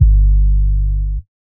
father of 4 paris red room session 808.wav